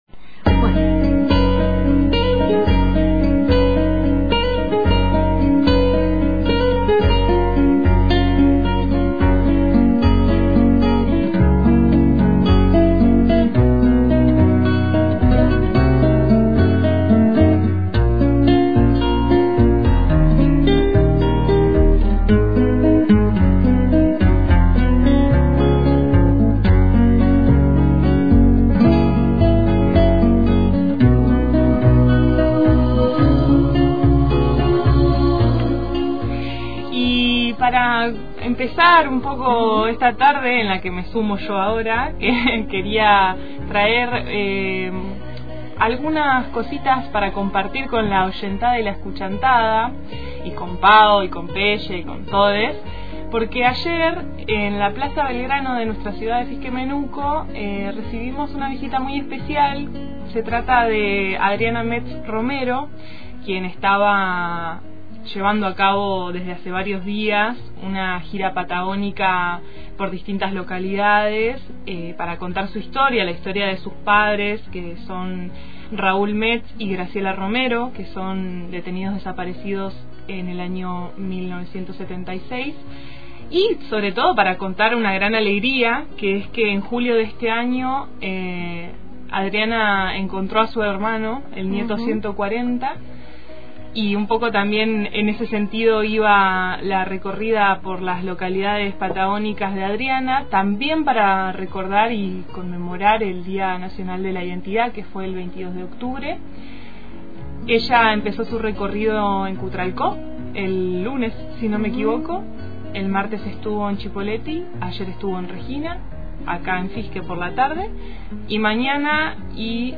crónica
Escuchá los testimonios